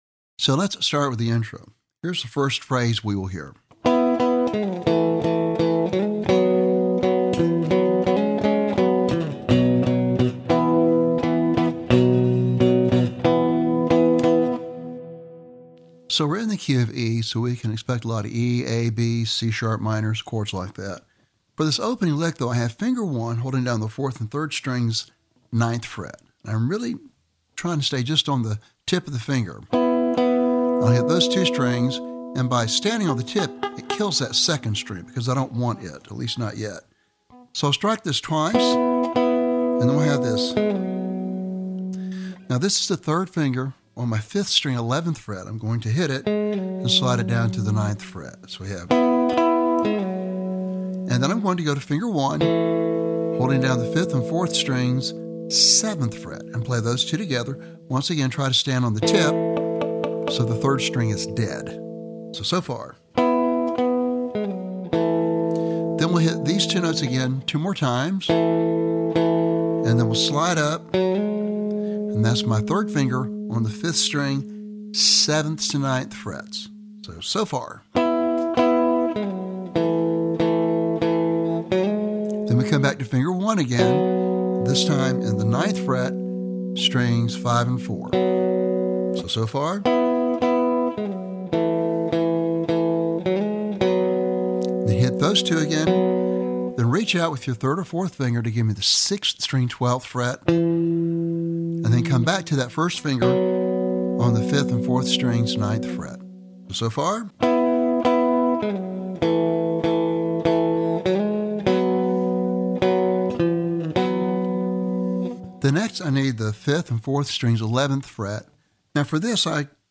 Lesson Sample